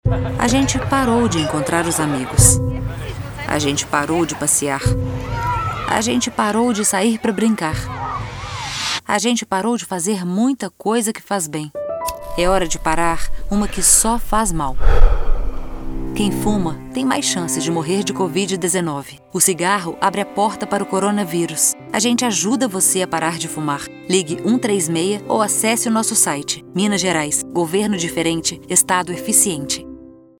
SPOT_tabagismo.mp3